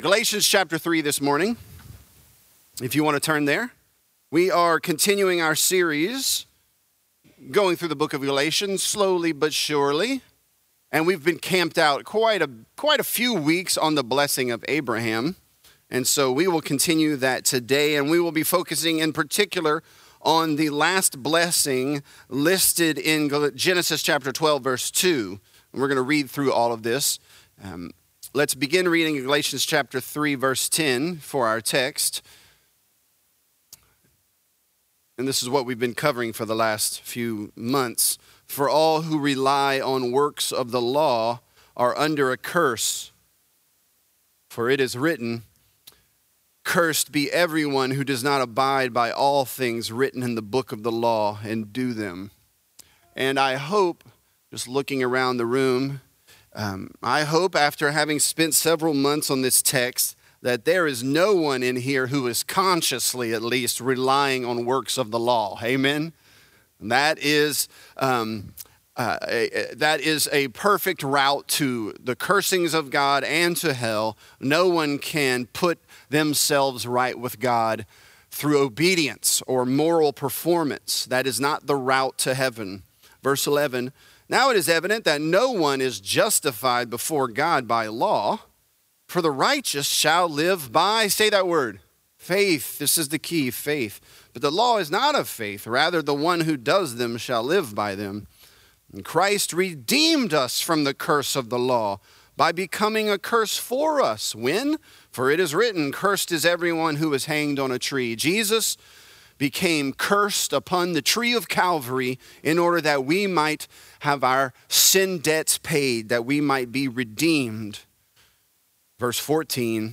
This is a part of our sermon series on the book of Galatians.